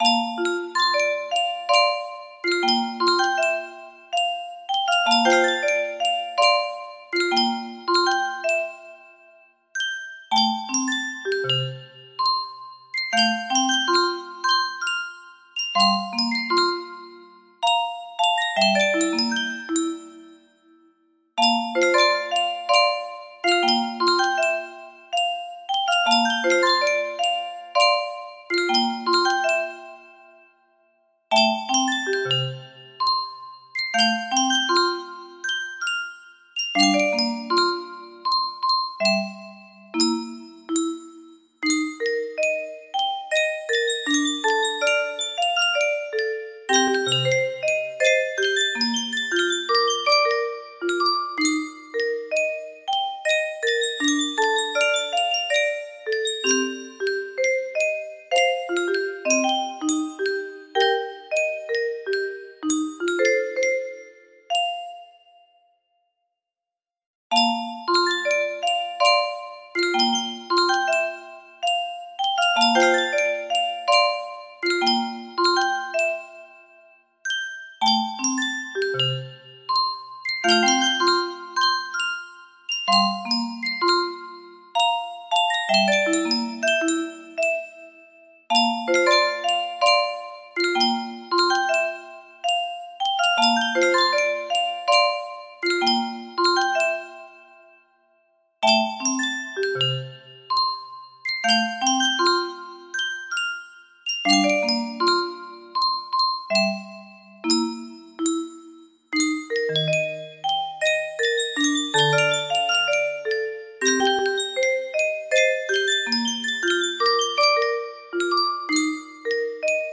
Full range 60
arranged a bit with a 3/4 delay.